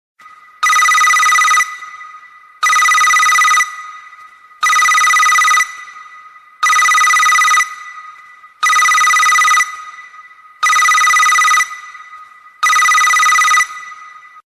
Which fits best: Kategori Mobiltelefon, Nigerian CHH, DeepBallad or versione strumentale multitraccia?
Kategori Mobiltelefon